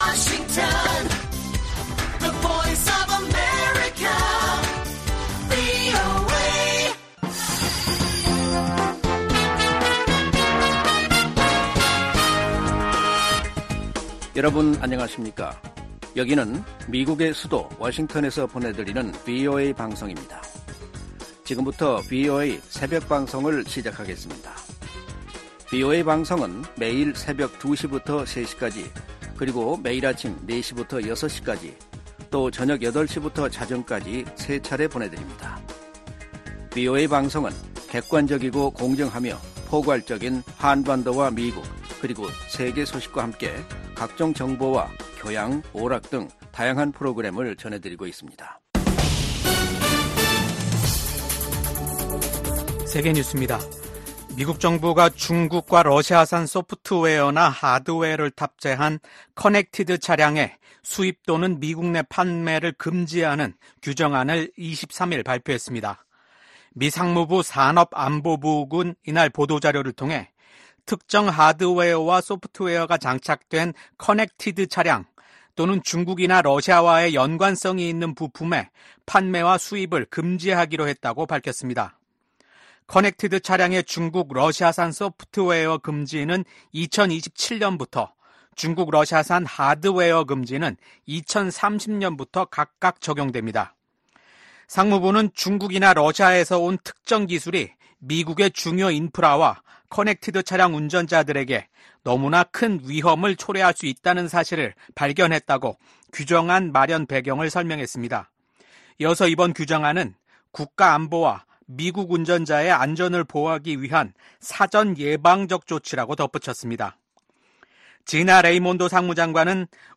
VOA 한국어 '출발 뉴스 쇼', 2024년 9월 21일 방송입니다. 미국과 일본, 호주, 인도 정상들이 북한의 미사일 발사와 핵무기 추구를 규탄했습니다. 미국 정부가 전쟁포로 실종자 인식의 날을 맞아 미군 참전용사를 반드시 가족의 품으로 돌려보낼 것이라고 강조했습니다. 유엔 북한인권특별보고관이 주민에 대한 통제 강화 등 북한의 인권 실태가 더욱 열악해지고 있다는 평가를 냈습니다.